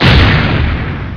bomb1.wav